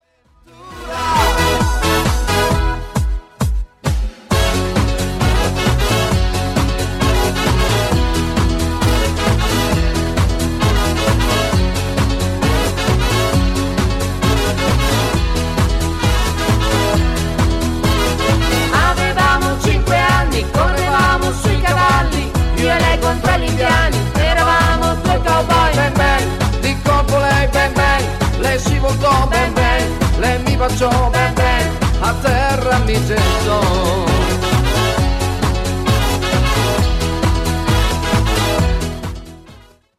DANCE  (7.31)